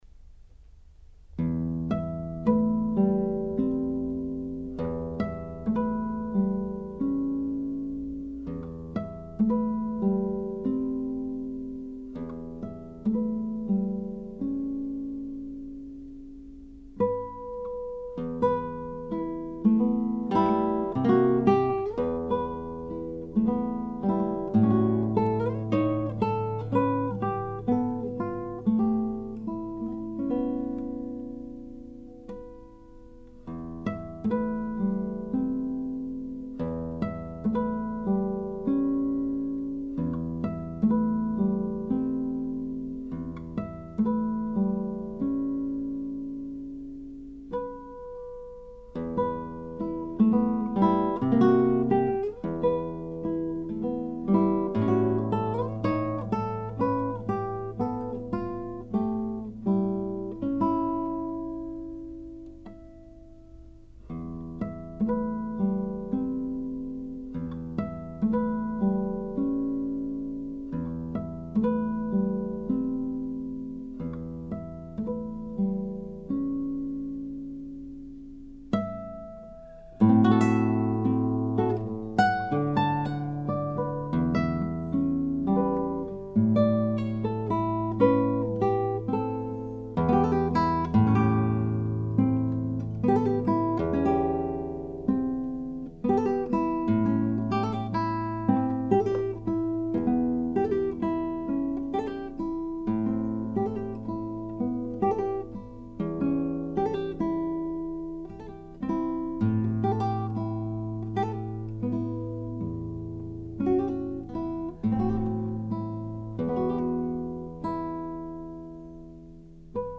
Tôi nằm nhìn trời sao rất lâu, không chợp mắt được, và bất chợt một giai điệu đơn sơ như một lời hát ru đến trong trí tôi. Những ngày sau đó, giai điệu ấy cứ ngân nga trong tôi, rồi dần dần có hình hài trọn vẹn, và tôi chép lại thành nhạc khúc này cho tây ban cầm độc tấu.